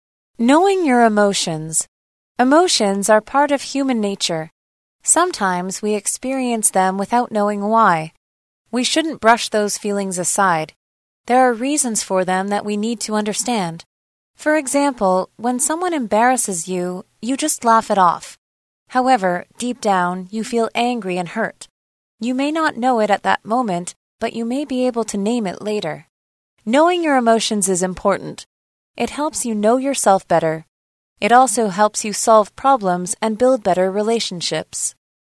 朗讀題目及練習音檔請參閱附加檔案~~